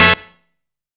BRASSY STAB.wav